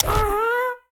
Minecraft Version Minecraft Version 25w18a Latest Release | Latest Snapshot 25w18a / assets / minecraft / sounds / mob / happy_ghast / goggles_up.ogg Compare With Compare With Latest Release | Latest Snapshot
goggles_up.ogg